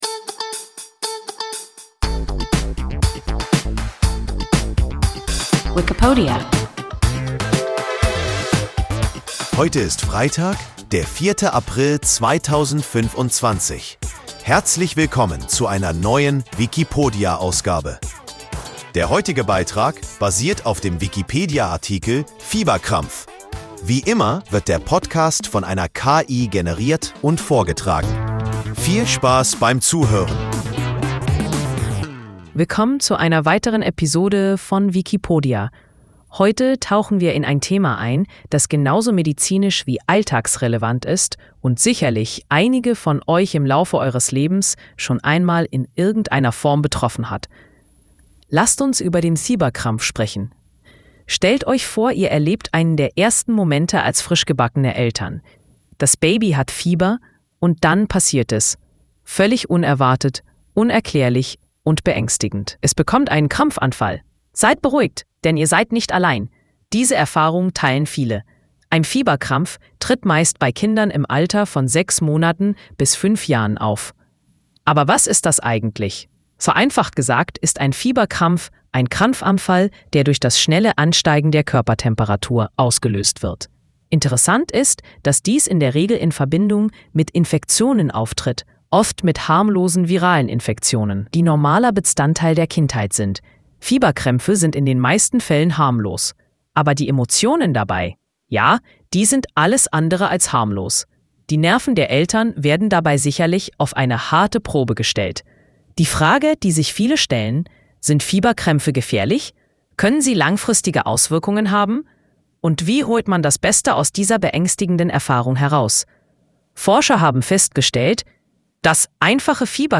Fieberkrampf – WIKIPODIA – ein KI Podcast